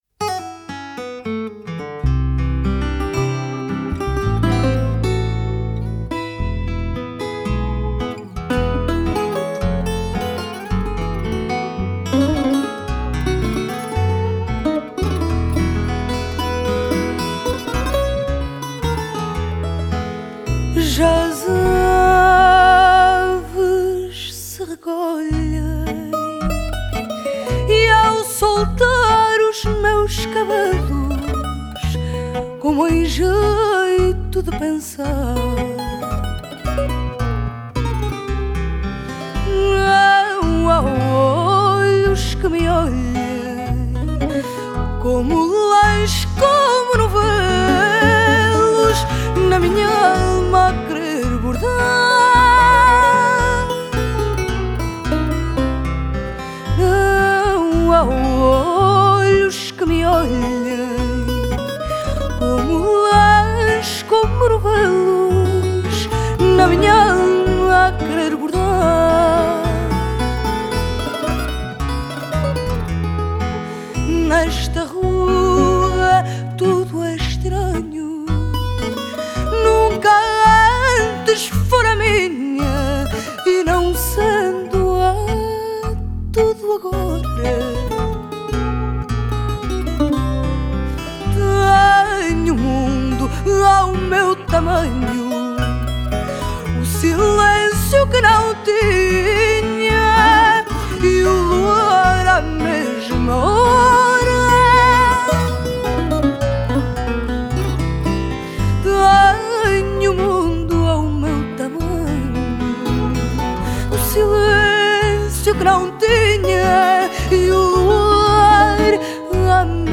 Genre: Fado, Folk, Portuguese music